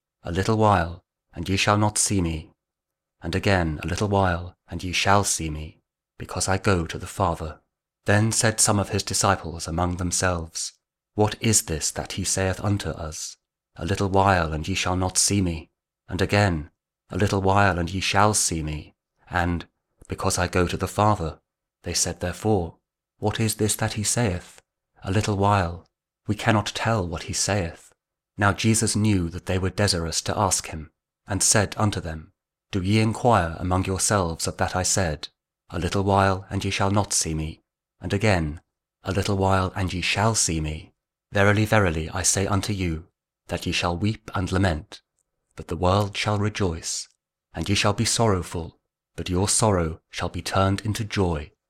John 16: 16-20 | King James Audio Bible | Daily Verses